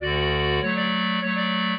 clarinet
minuet0-10.wav